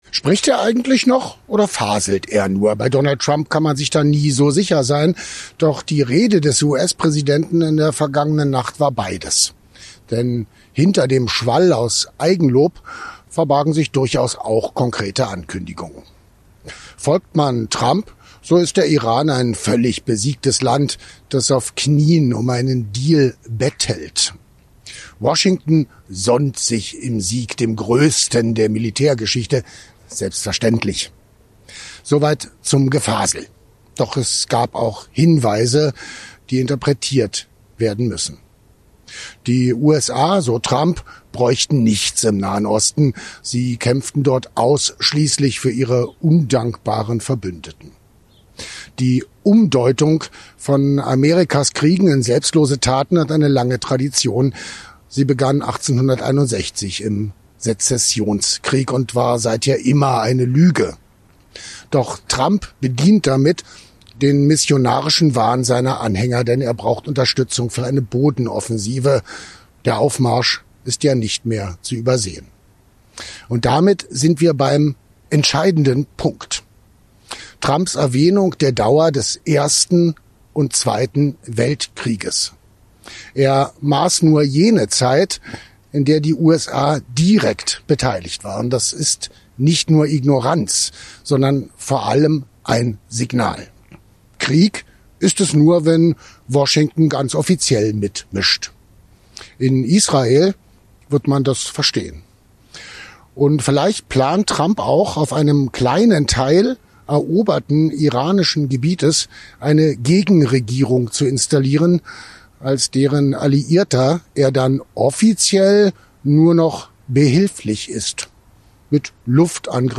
Kommentar: Trump will den Bodenkrieg